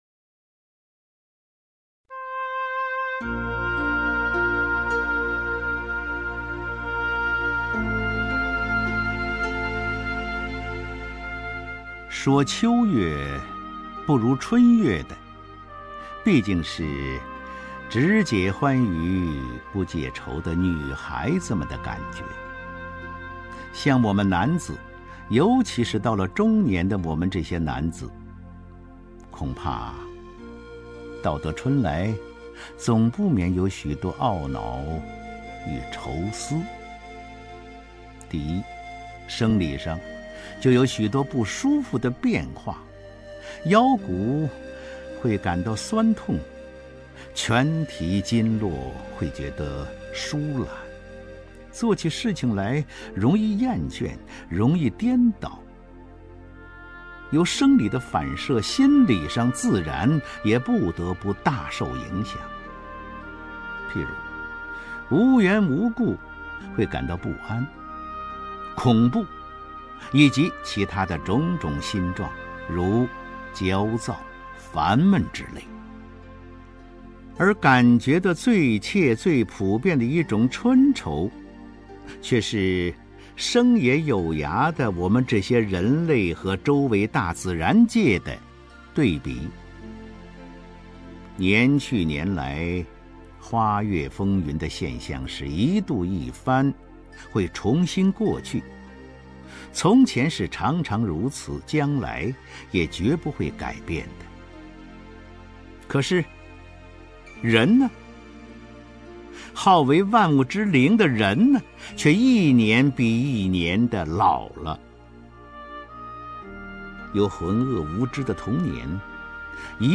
首页 视听 名家朗诵欣赏 张家声
张家声朗诵：《春愁》(郁达夫)